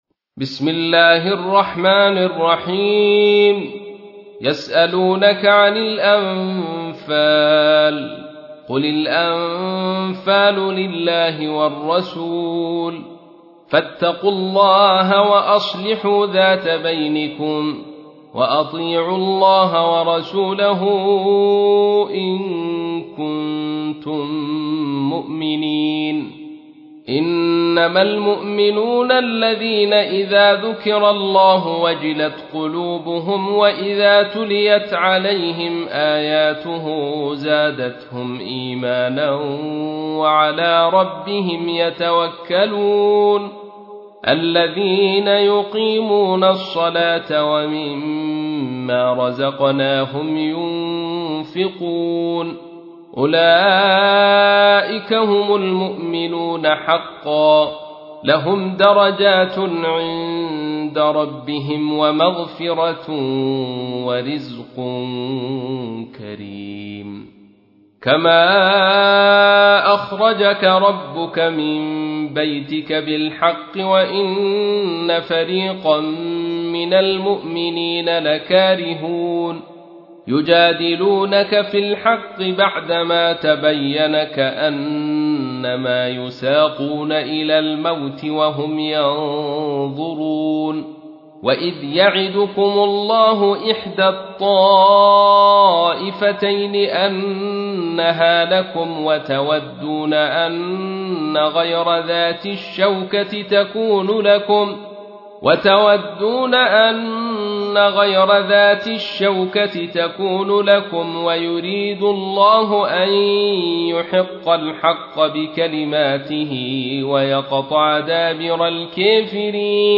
تحميل : 8. سورة الأنفال / القارئ عبد الرشيد صوفي / القرآن الكريم / موقع يا حسين